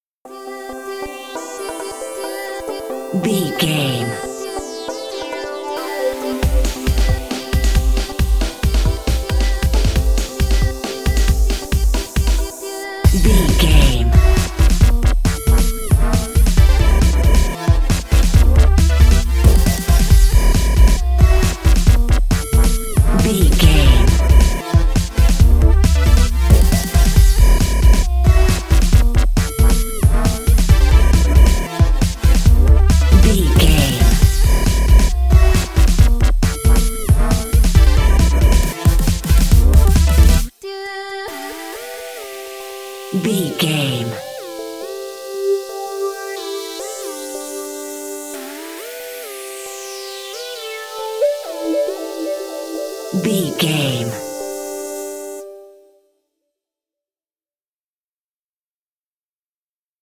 Epic / Action
Fast paced
Mixolydian
aggressive
powerful
dark
funky
groovy
futuristic
driving
energetic
drum machine
synthesiser
breakbeat
synth leads
synth bass